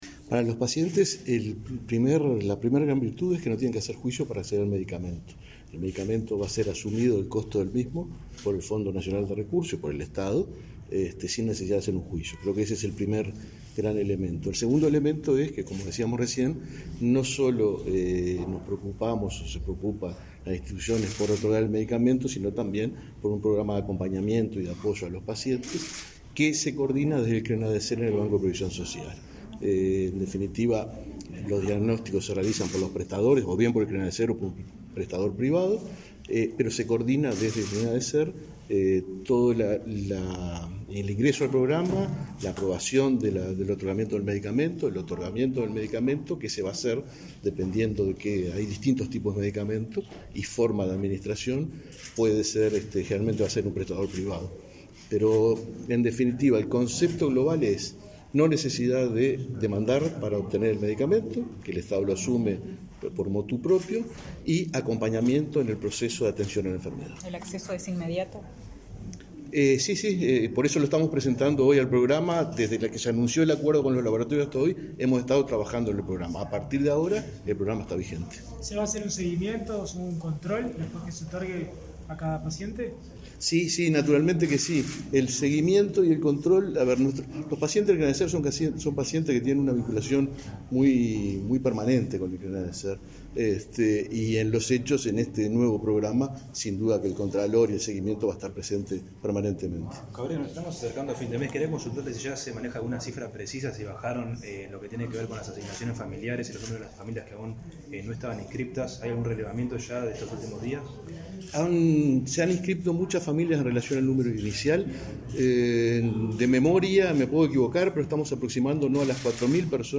Declaraciones del presidente del BPS a la prensa
Declaraciones del presidente del BPS a la prensa 23/08/2022 Compartir Facebook X Copiar enlace WhatsApp LinkedIn El presidente del Banco de Previsión Social (BPS), Alfredo Cabrera, dialogó con la prensa, luego de la conferencia en la que se informó sobre un nuevo programa de tratamiento para pacientes con atrofia muscular espinal.